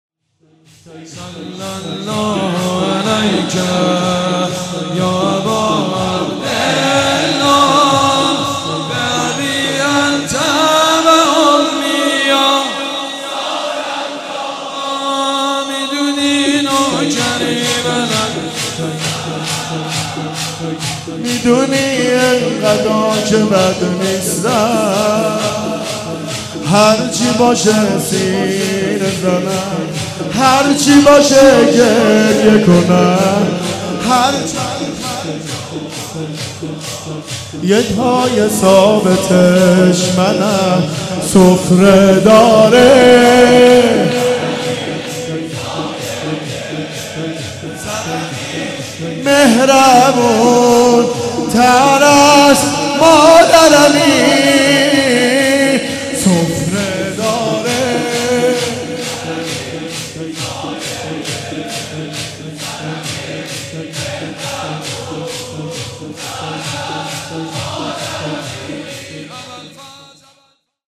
میدونی نوکری...(شور)